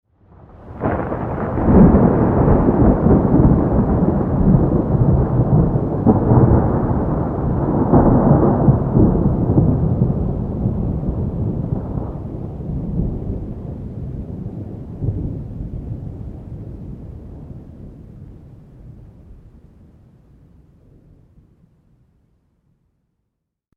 Удар грома с раскатами, на фоне идет дождь Скачать звук music_note Гроза , Раскаты грома save_as 574.4 Кб schedule 0:36:00 6 2 Теги: mp3 , гроза , Гром , Дождь , звук , погода , раскаты грома , шум дождя